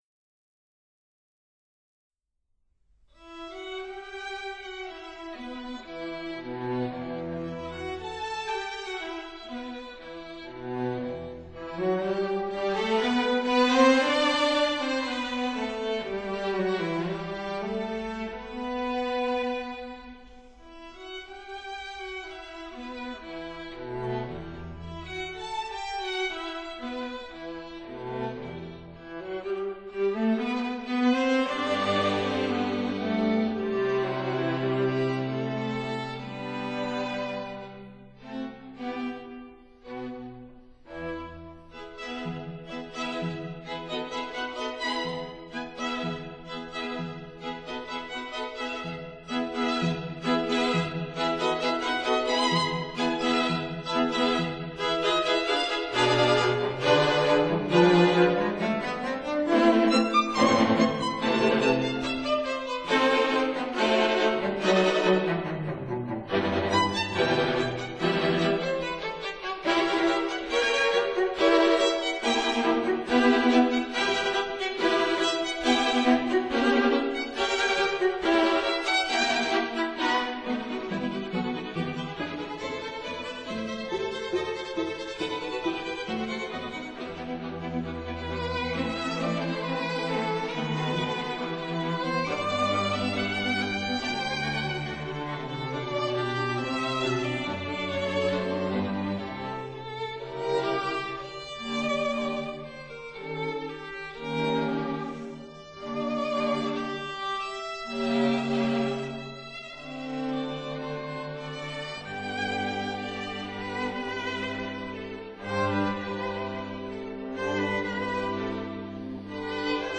violins
violas
cello